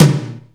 • Wet Tom Sample D Key 34.wav
Royality free tom sound tuned to the D note. Loudest frequency: 1049Hz
wet-tom-sample-d-key-34-YT0.wav